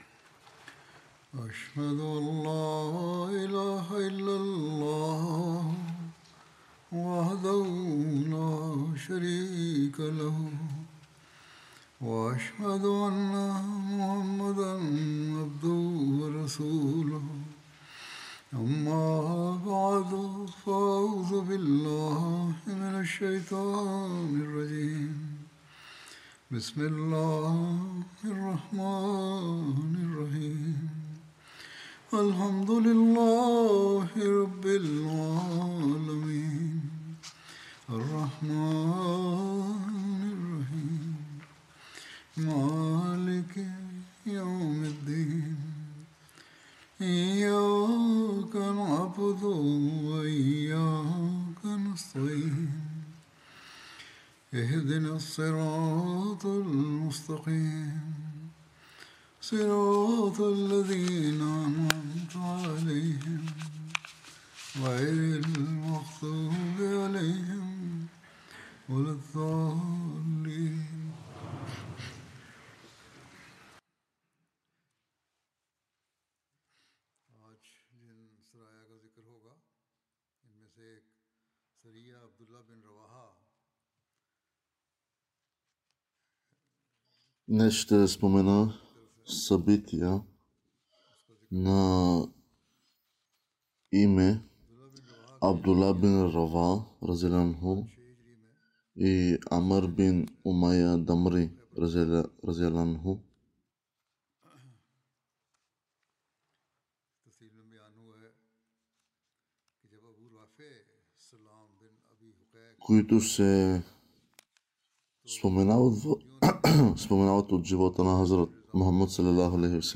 Bulgarian translation of Friday Sermon